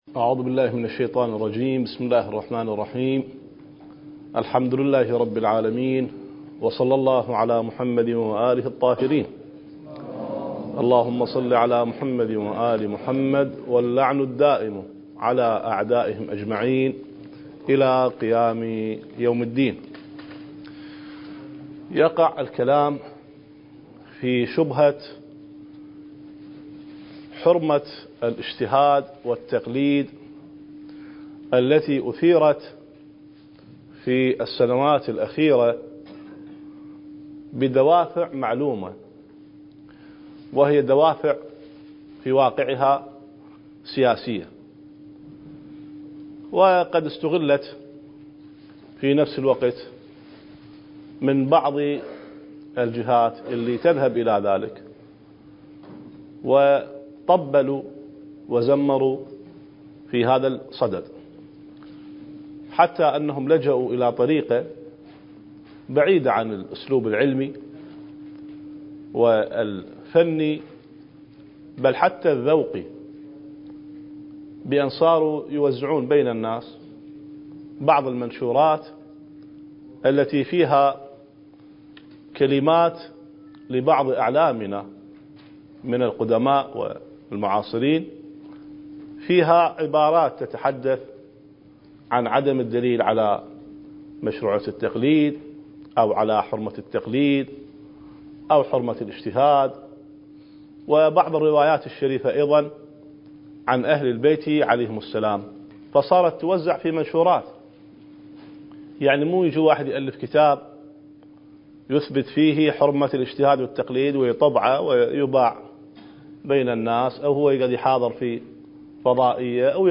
(المحاضرة الرابعة عشر)
المكان: النجف الأشرف